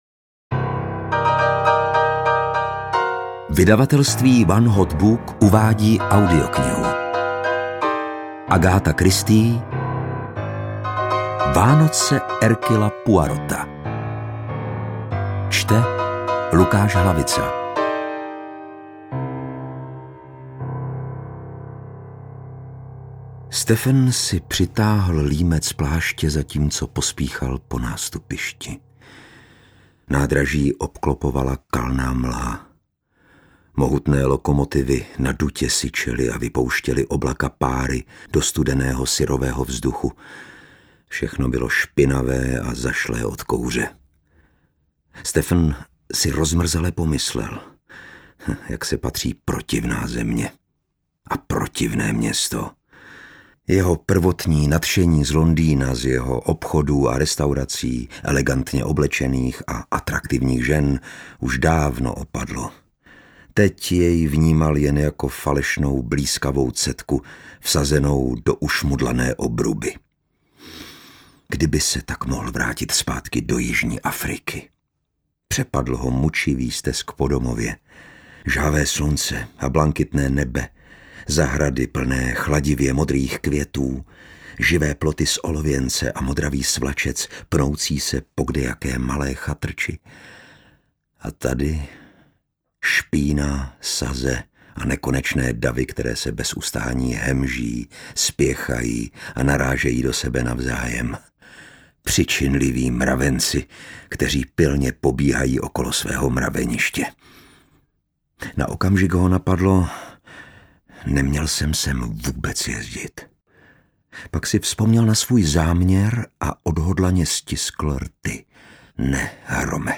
Vánoce Hercula Poirota audiokniha
Ukázka z knihy